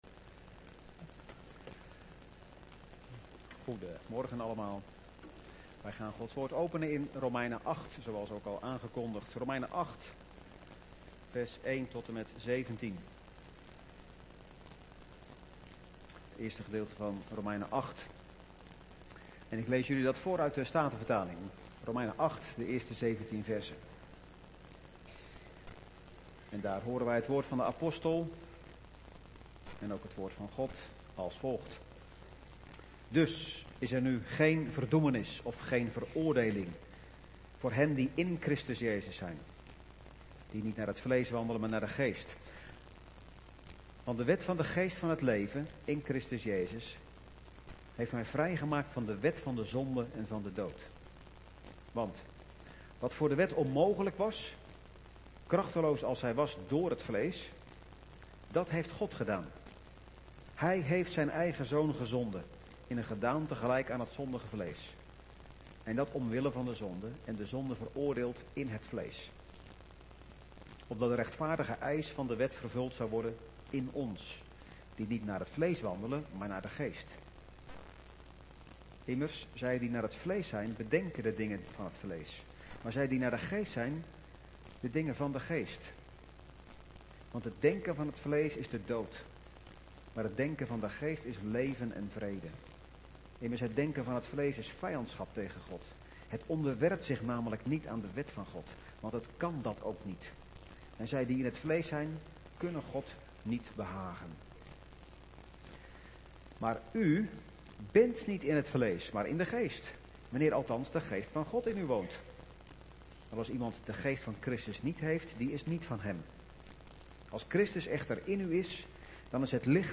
Laatste video-preek